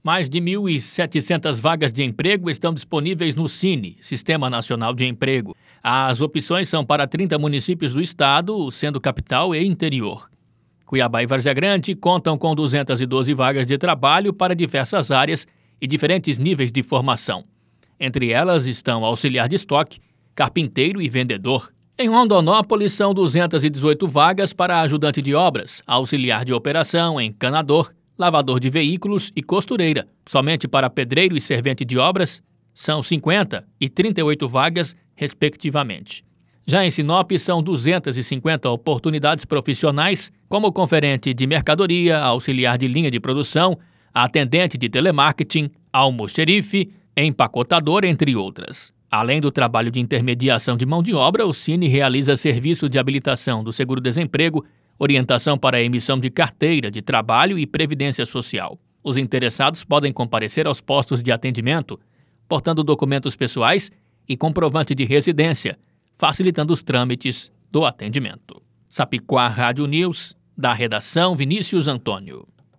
Boletins de MT 10 mar, 2022